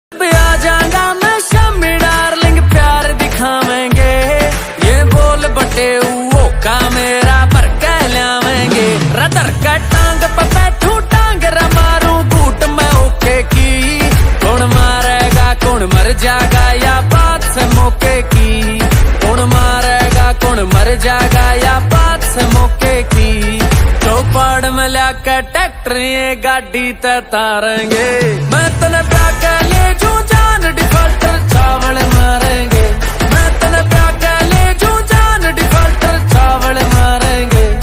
New Haryanvi Song